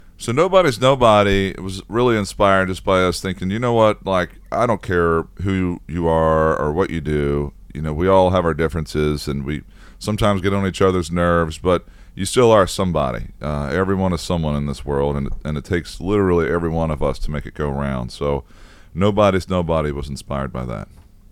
Audio / BROTHERS OSBORNE'S TJ OSBORNE TALKS ABOUT THE DUO'S SONG, "NOBODY'S NOBODY."